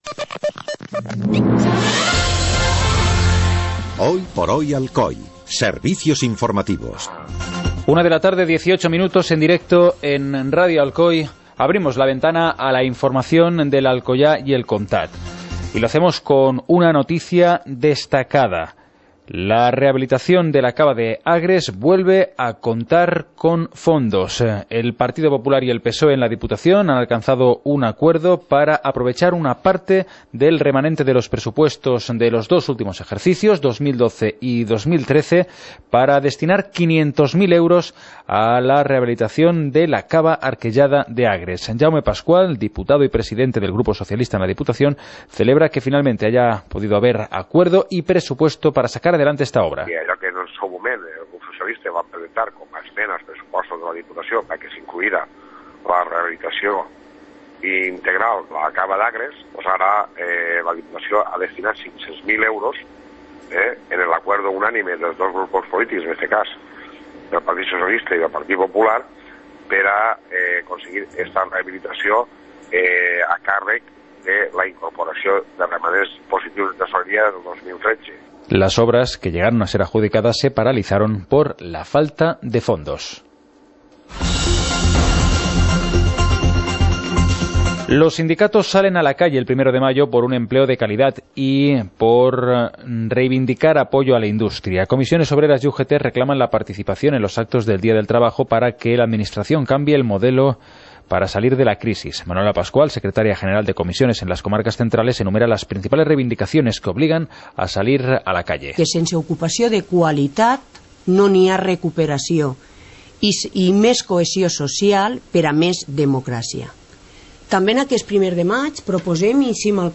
Informativo comarcal - lunes, 28 de abril de 2014